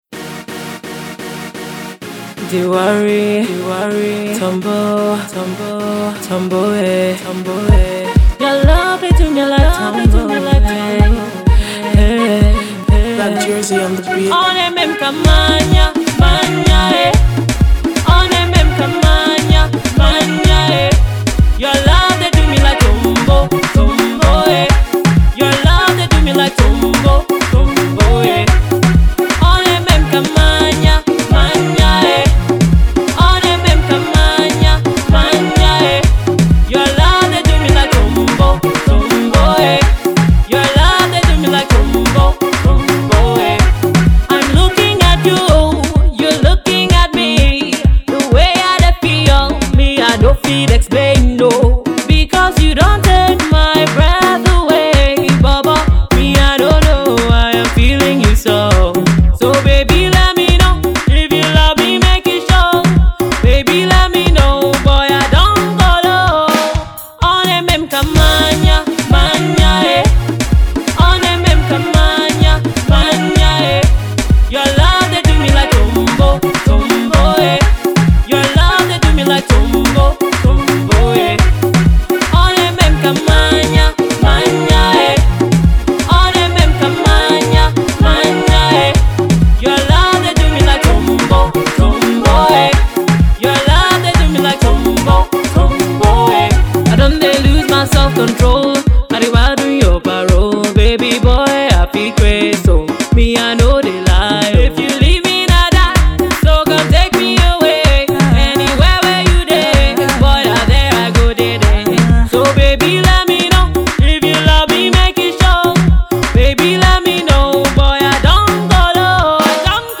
this talented singer
unique angle and vocal talent